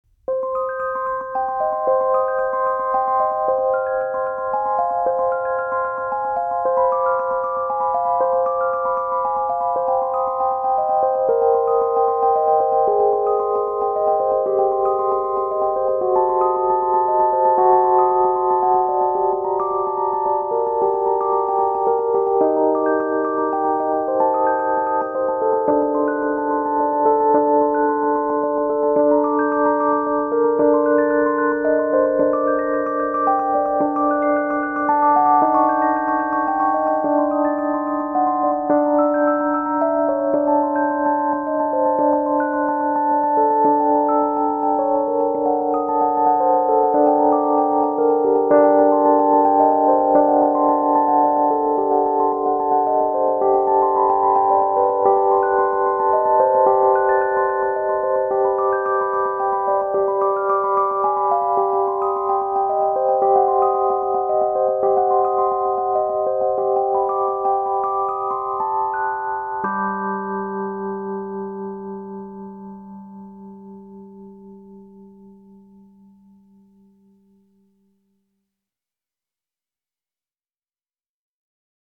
Rhodes Mark 7 passive with Delta-Trem (((STEREO))) – Johann Sebastian Bach Preludio in do min. BWV 999.